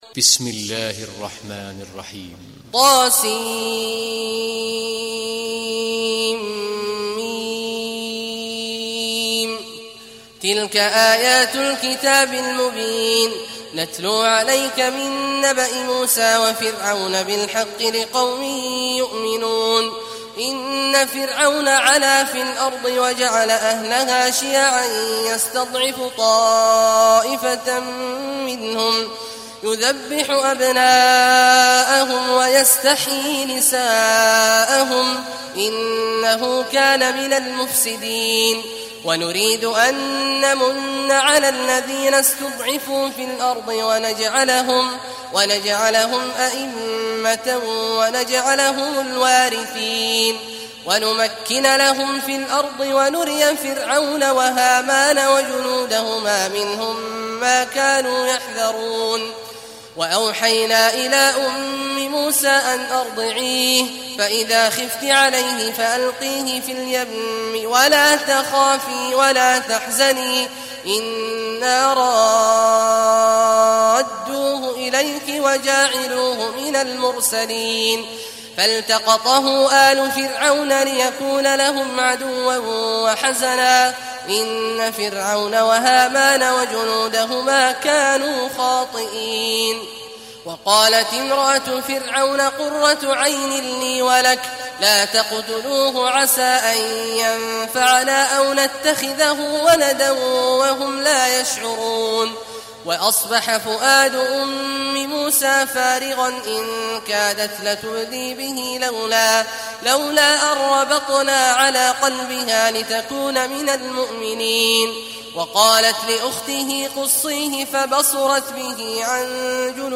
Surat Al Qasas mp3 Download Abdullah Awad Al Juhani (Riwayat Hafs)